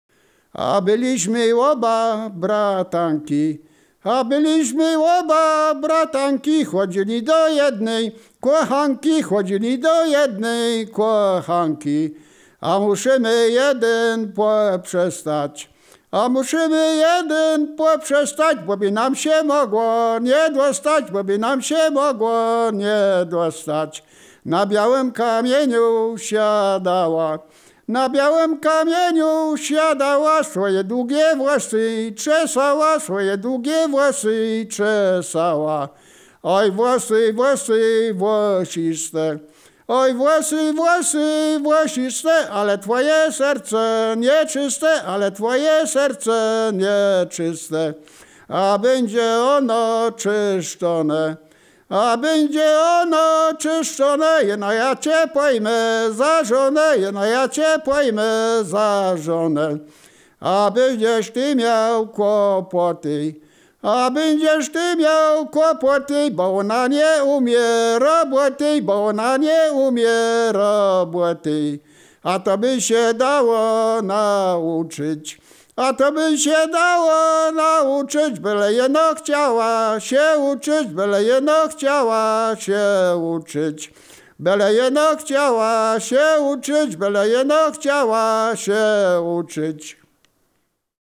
Wielkopolska
województwo wielkopolskie, powiat gostyński, gmina Krobia, wieś Stara Krobia
liryczne miłosne